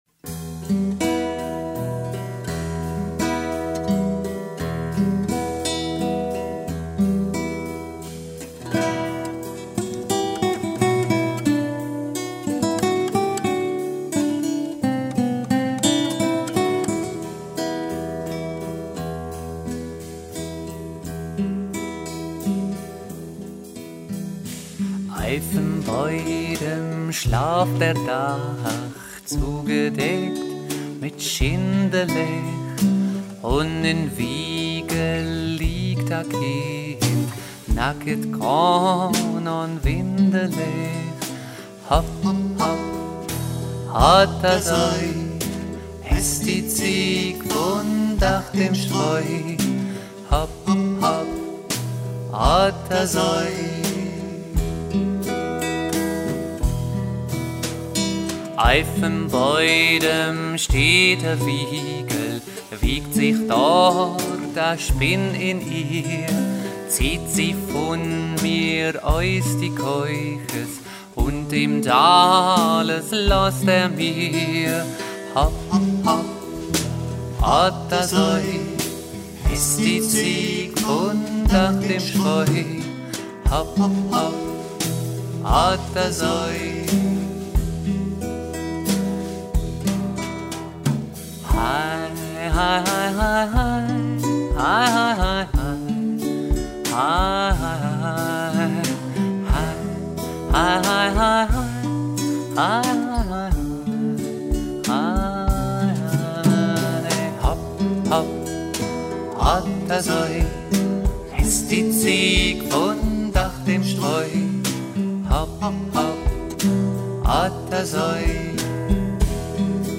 schwungvolle und bewegende Musik.
Das Quartett besteht aus vier gestandenen Musikpädagogen.